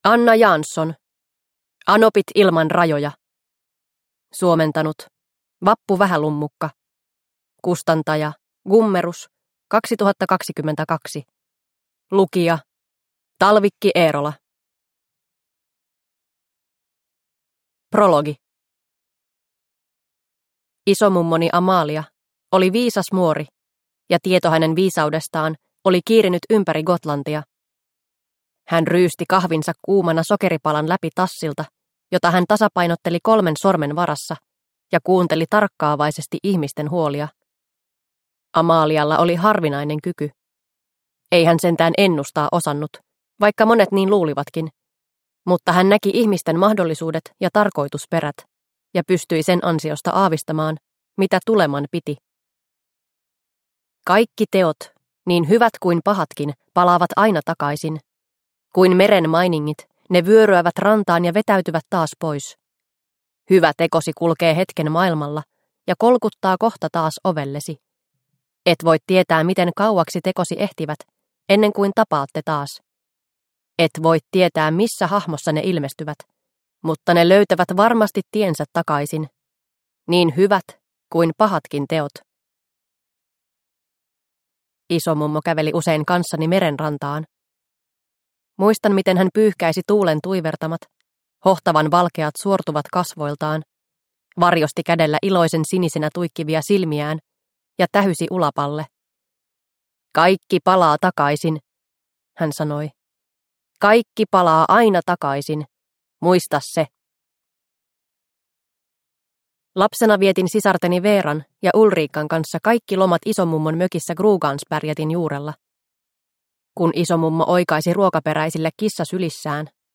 Anopit ilman rajoja – Ljudbok – Laddas ner